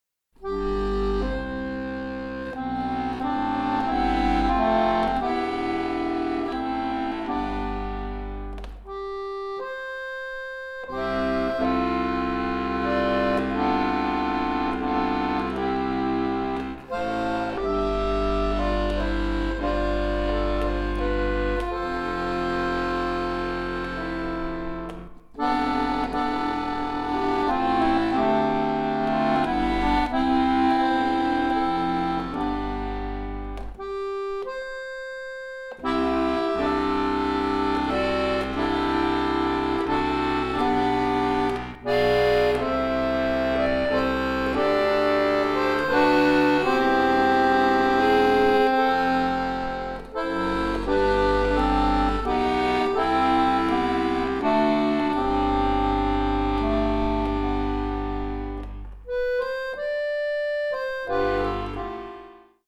Akkordeon Solo